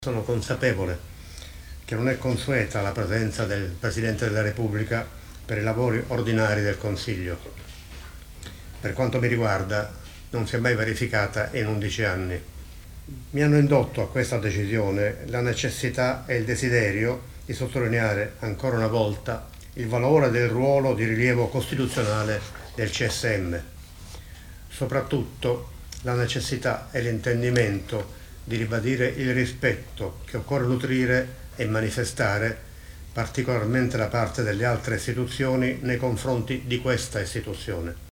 Mattarella Assemblea plenaria del Consiglio Superiore della Magistratura
L’intervento del presidente Sergio Mattarella: